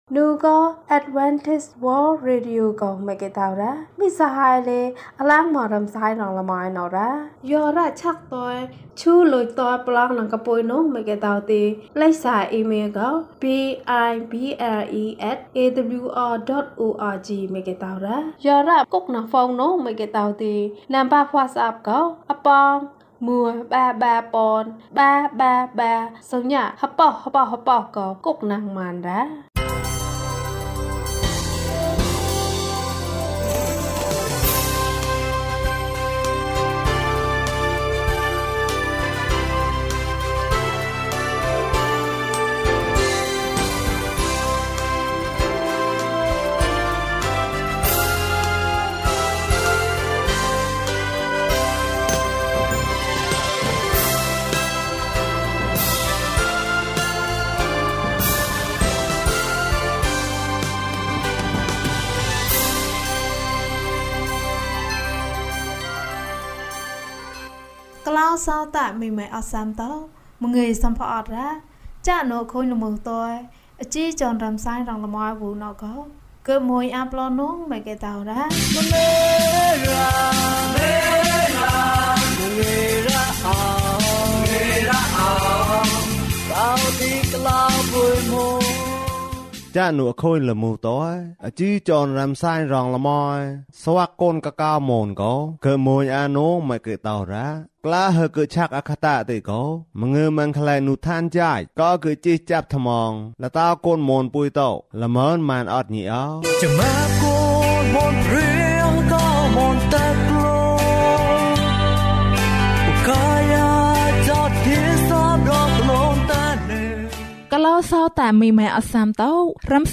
ယေရှုနှင့်အတူ ဆုတောင်းပါ၏။ အပိုင်း ၁ ကျန်းမာခြင်းအကြောင်းအရာ။ ဓမ္မသီချင်း။ တရားဒေသနာ။